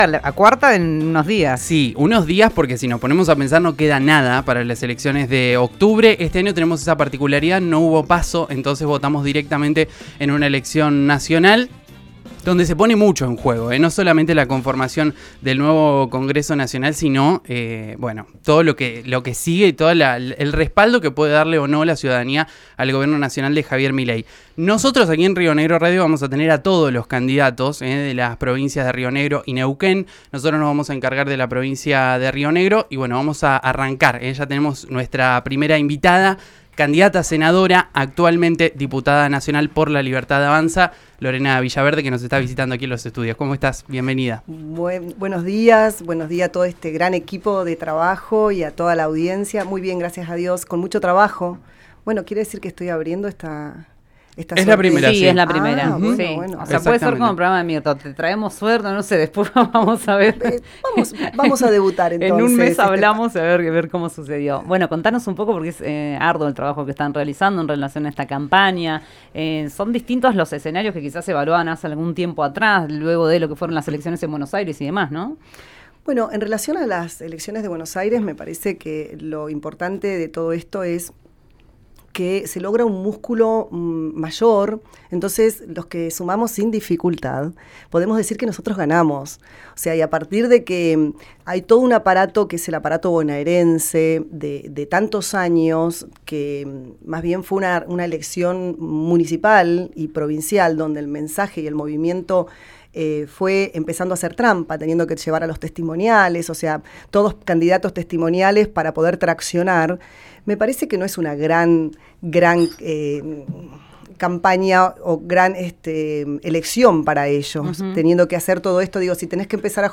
Durante una extensa entrevista en RN Radio, la actual diputada nacional defendió la gestión del presidente Javier Milei y lanzó duras acusaciones contra el peronismo rionegrino, centrando sus críticas en la figura del diputado Martín Soria y su familia.